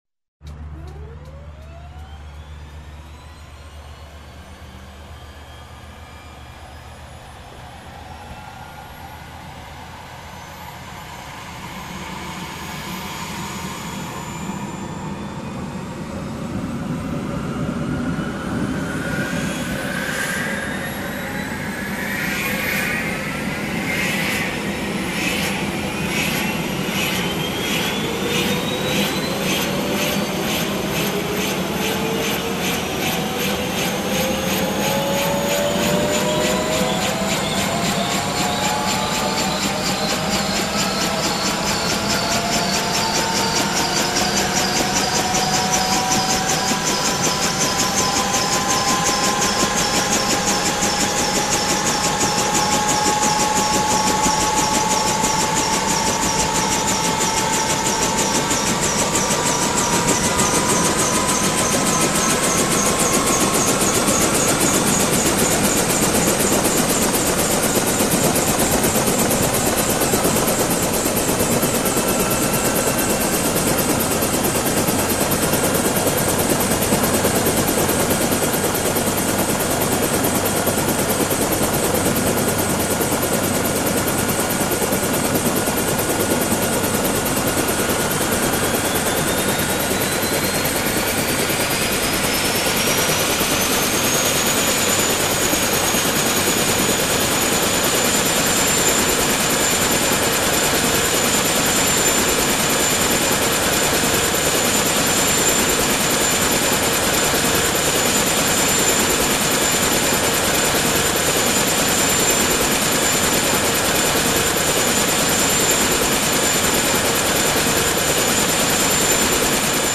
Звуки вертолетов
Гул запускающегося вертолета